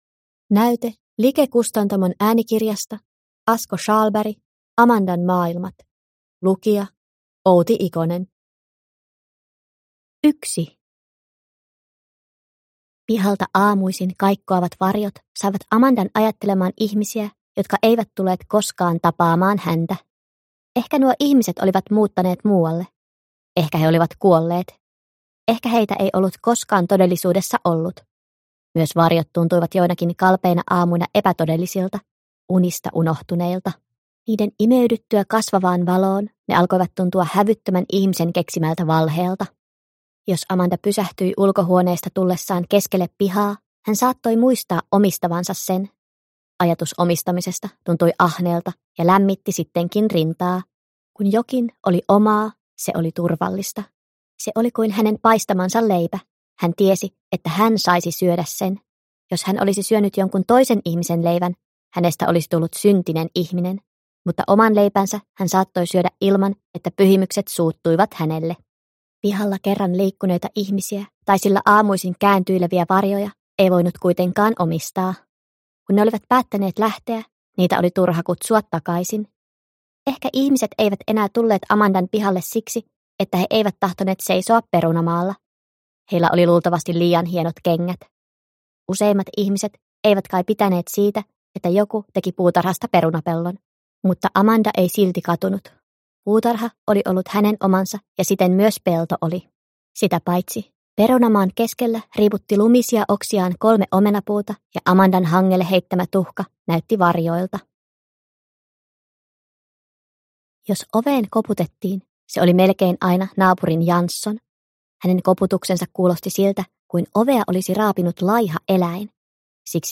Amandan maailmat – Ljudbok – Laddas ner